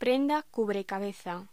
Locución: Prenda cubrecabeza
locución
Sonidos: Voz humana